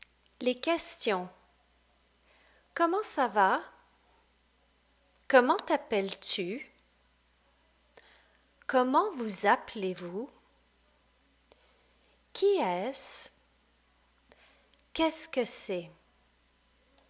(phonetically)